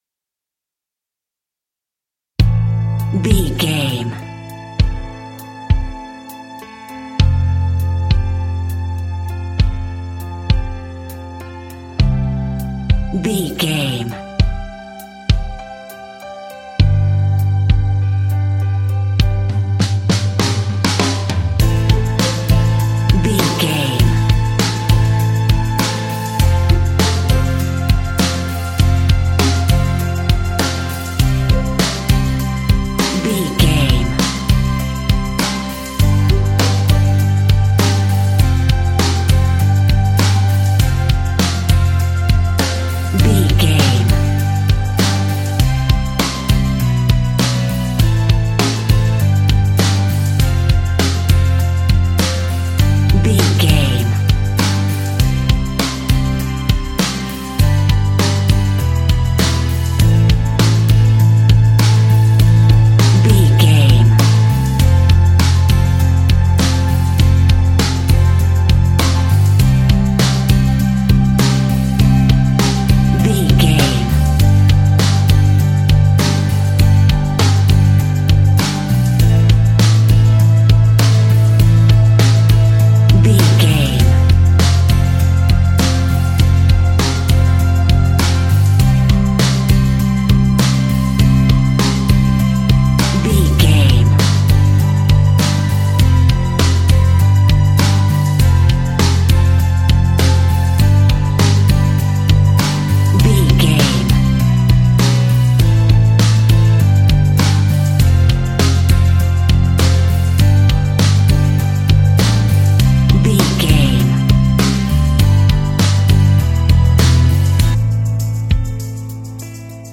Ionian/Major
calm
happy
energetic
smooth
soft
uplifting
electric guitar
bass guitar
drums
pop rock
indie pop
instrumentals
organ